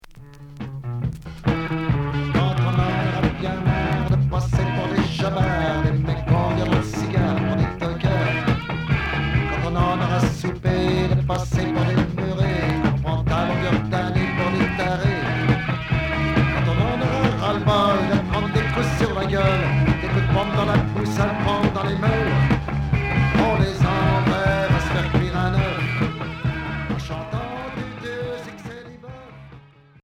Rock et chanson Deuxième 45t retour à l'accueil